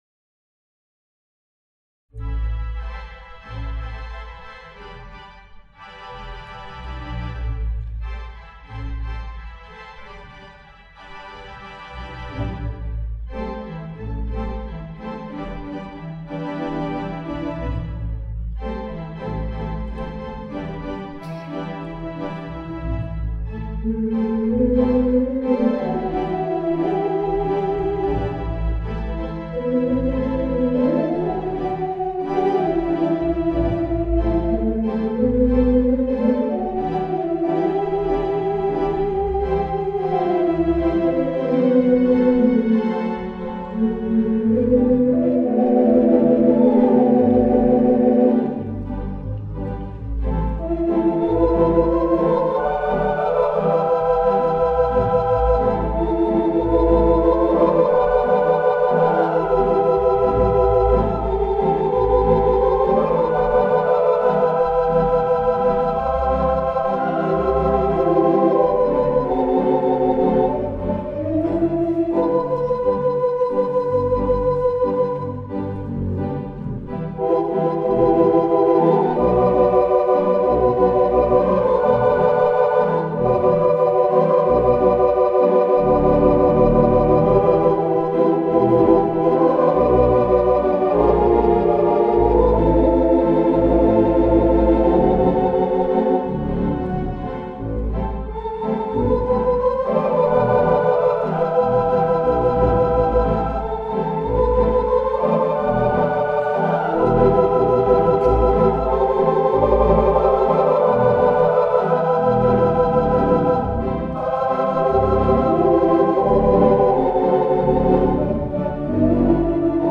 Palace Theatre in Canton, Ohio.
This Theatre Pipe Organ has three manuals and eleven ranks.
The instrument is comfortable to sit at and play, and the sound is powerful and symphonic in the atmospheric auditorium where it lives.
The 3/11 Mighty Kilgen Theatre Pipe Organ installed at the Palace Theatre sounds like a symphony orchestra with a classical flare.
Ohio during the Ohio Organ Crawl.